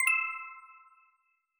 CoinSFX.wav